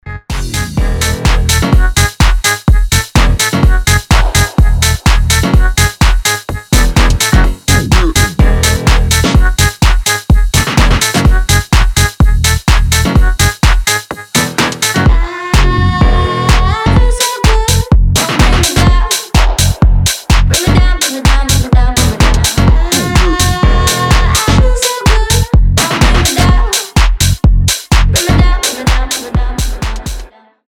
• Качество: 320, Stereo
ритмичные
Electronic
EDM
Tech House